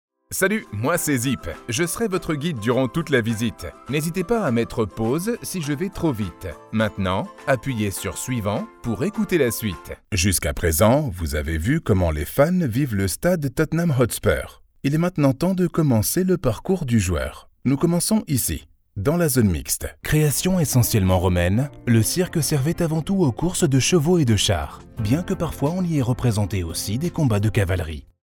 Comercial, Natural, Versátil, Amable, Empresarial
Audioguía
A true "chameleon" voice, he adapts his tone and delivery to convey the perfect message.